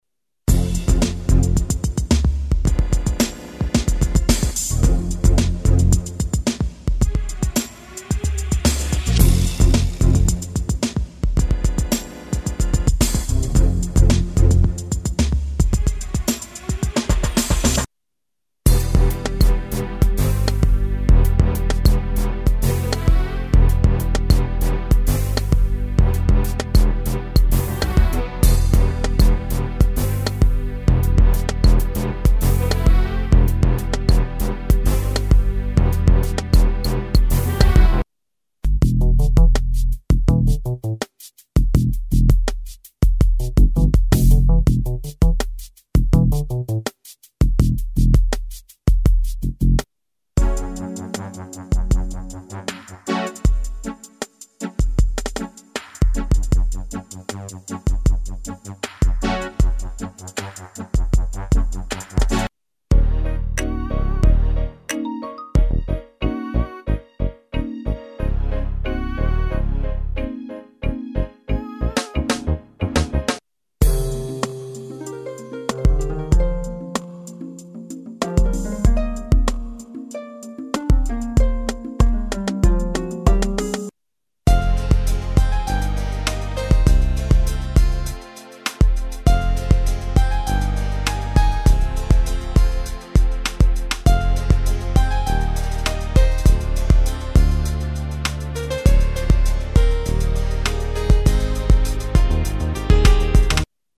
Rap Hip-Hop Loops 1
Gangsta Liks is a compilation of beats, keys, electronic bass, drums, percussion and synthesizers inspired by artists like Dr. Dre,Grandmaster Melle Mel & the Furious 5, Jam Master J, A Tribe Called Quest, Biz Markie & OutKast.
Compatible with Apple Garageband - Garageband loops